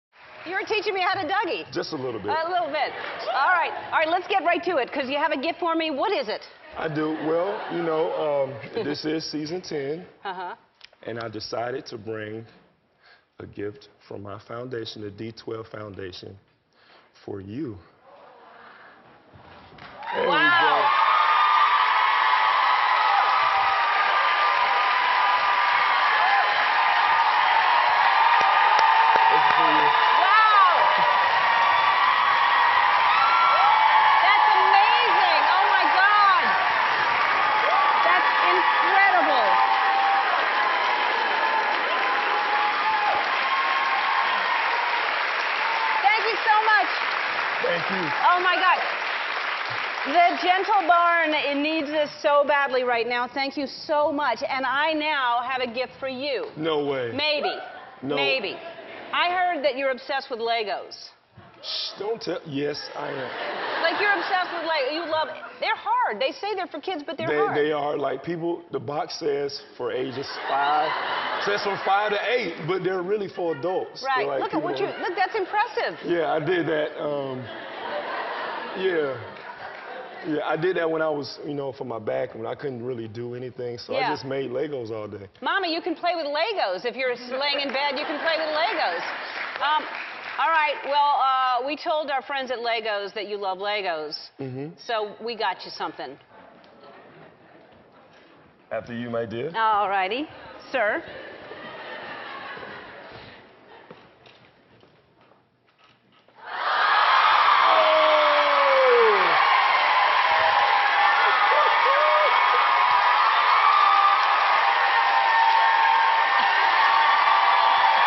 艾伦脱口秀09 访问球星霍德华 听力文件下载—在线英语听力室